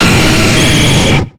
Cri de Nidoking dans Pokémon X et Y.